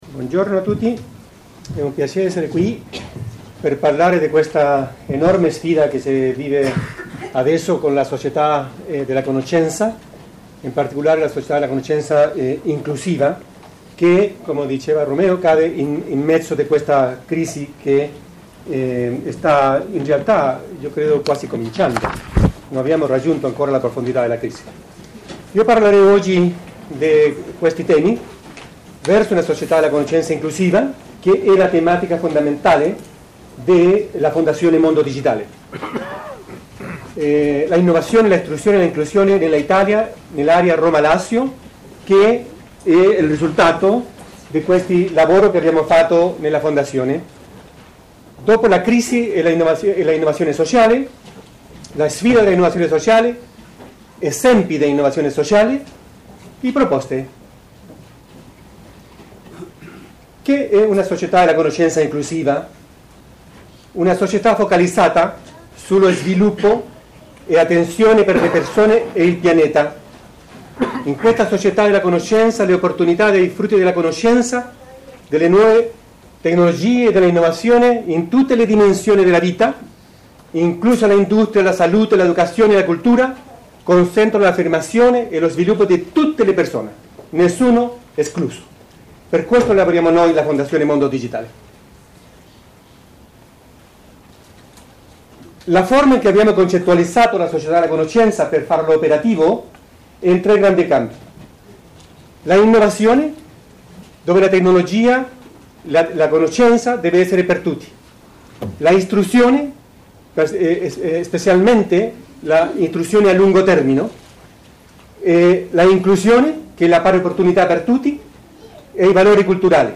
In occasione dell’incontro dibattito Cultura dell’innovazione e partecipazione (Roma, 10 dicembre, Associazione Civita, sala Al...